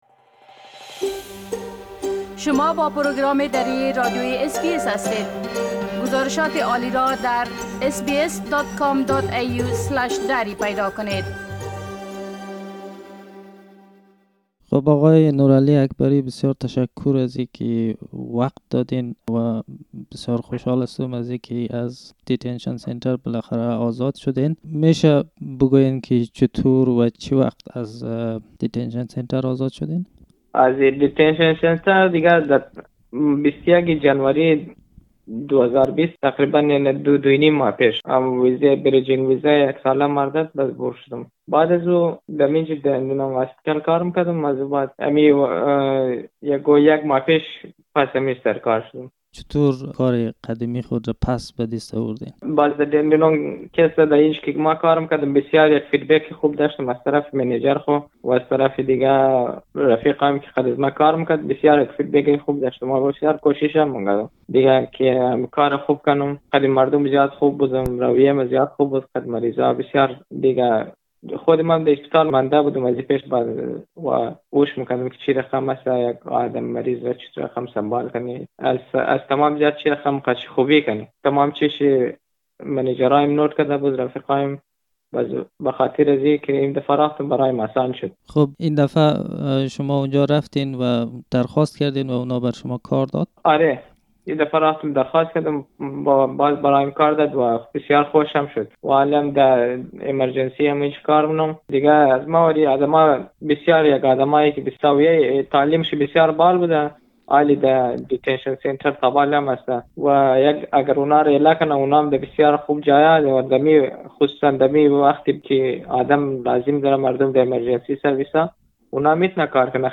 The interview is in the Dari language Soon after being released from a Melbourne Immigration Transit Accommodation